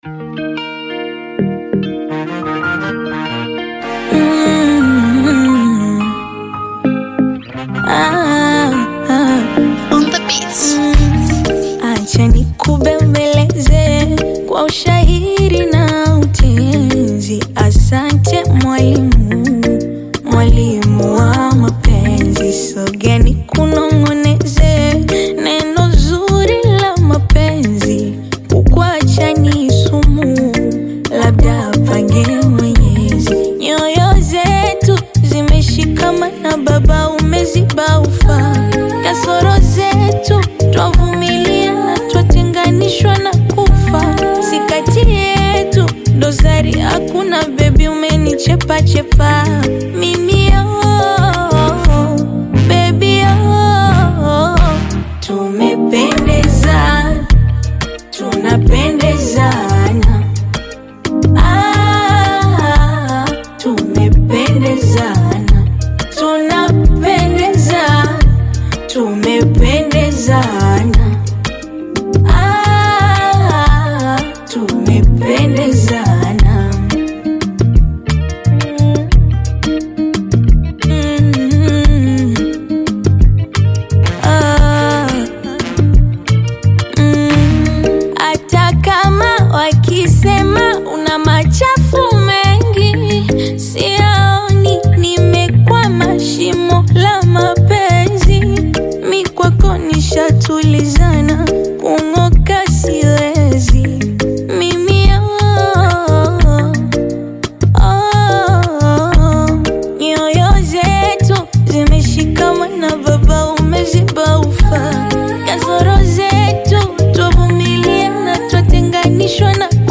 Tanzanian bongo flava artist, singer and songwriter
African Music